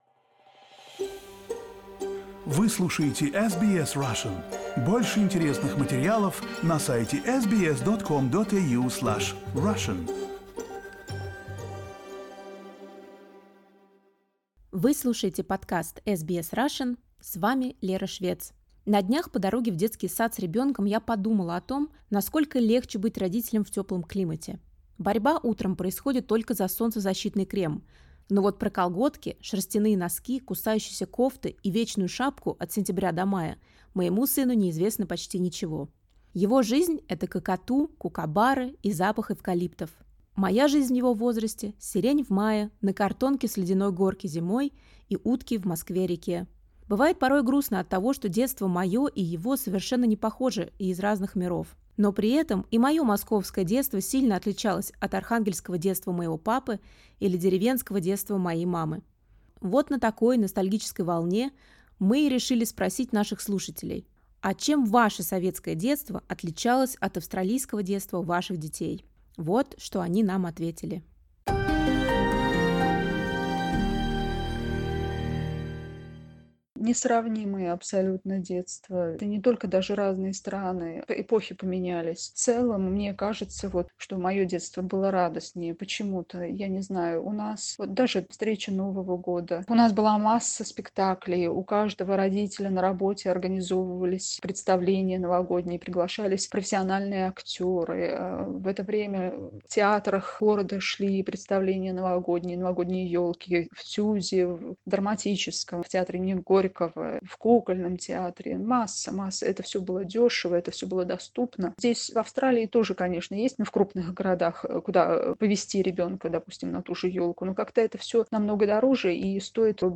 Vox-pop: Aussie vs Soviet childhood